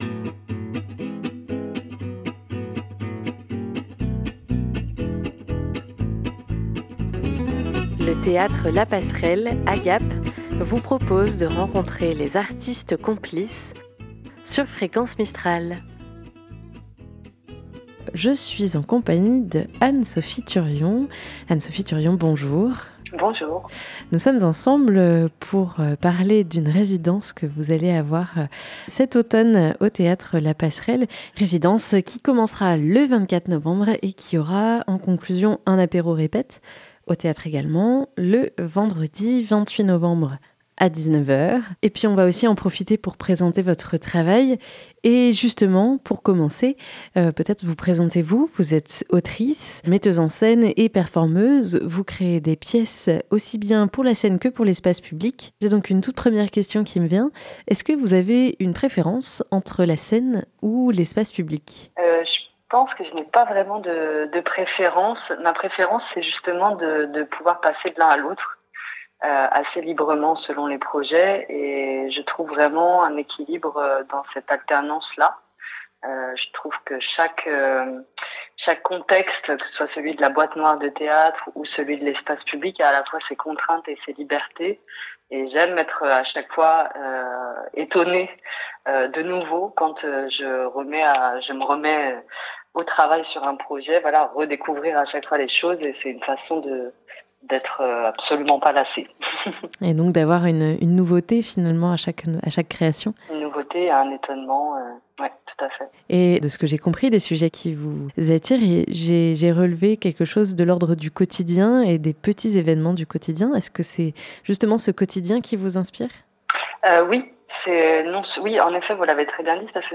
Cet été, elle a pris le temps de nous accorder une interview pour nous en parler.